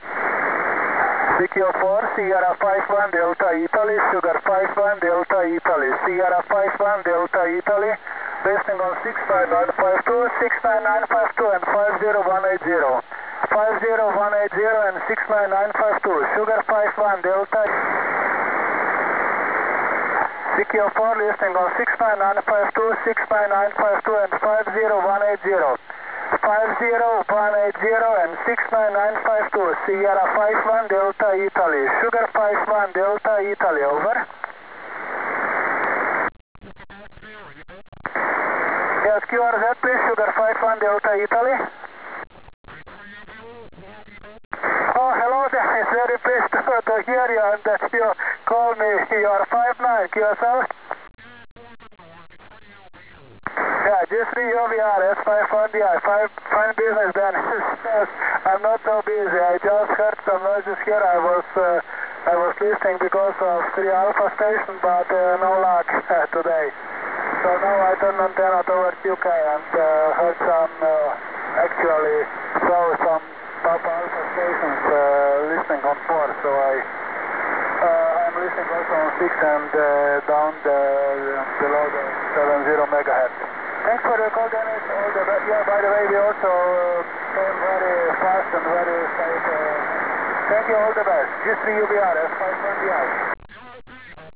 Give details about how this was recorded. Here are some sound clips of signals received summer 2010 on 70mhz.